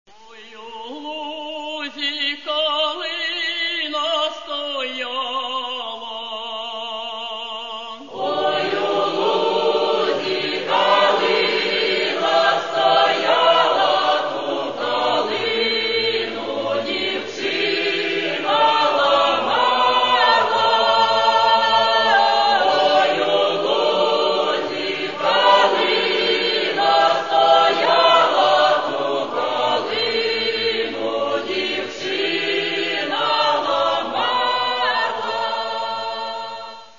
Catalogue -> Folk -> Traditional Solo Singing and Choirs